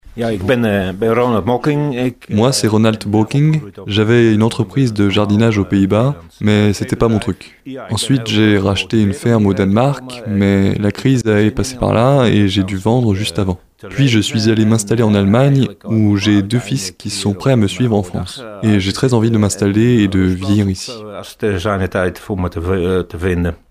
De quoi en motiver plus d’un, à l’image de ces deux éleveurs hollandais :